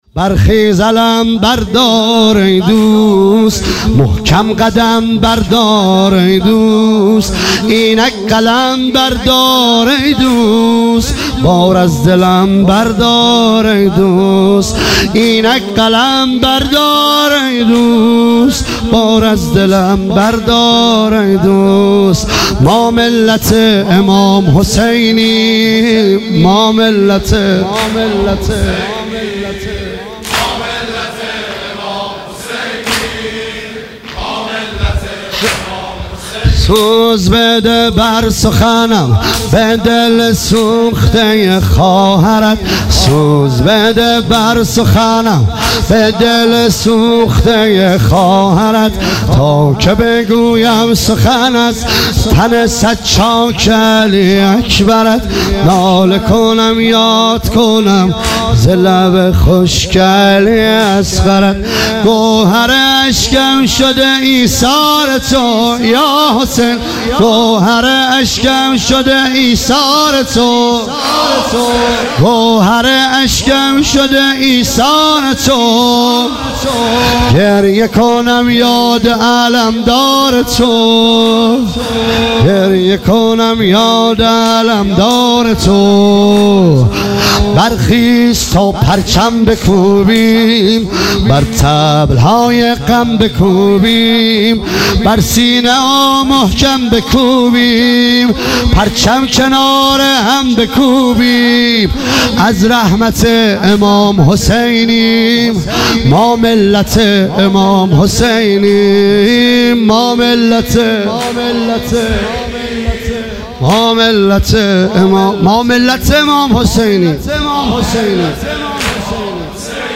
واحد تند